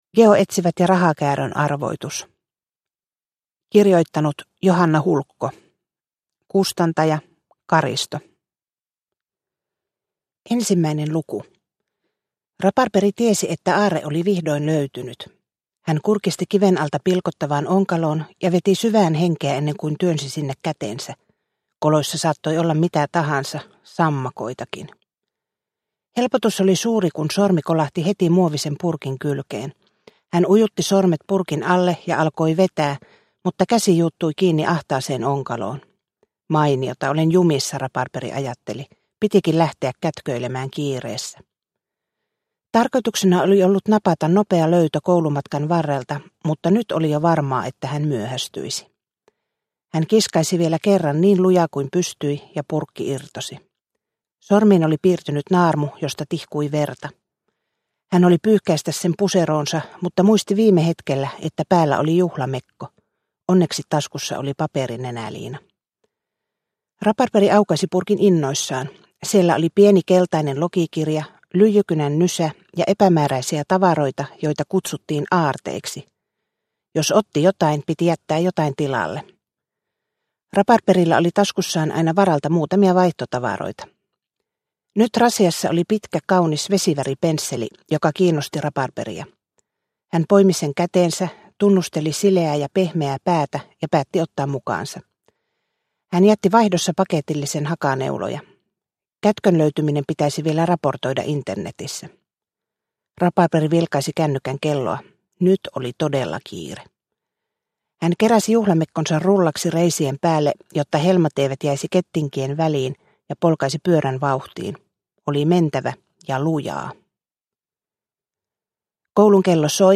Geoetsiva¨t ja rahakäärön arvoitus – Ljudbok – Laddas ner